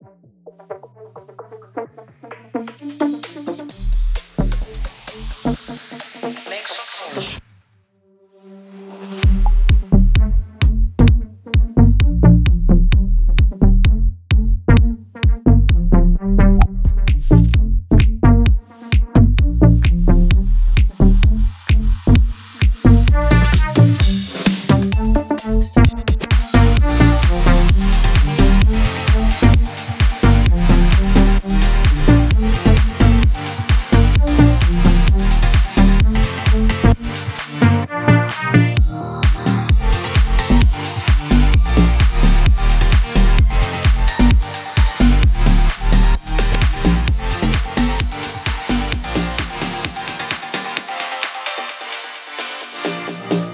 HOUSE.wav מבחינת המיקס זה ממש השלב ההתחלתי אבל אהבתי את העומק אגב אתם לא מאמינים איזה מקבצים אני בונה על הGENOS עם הסט החדש שלי אתם נופלים מהרגליים ברוך ה’ זה מתקדם יפה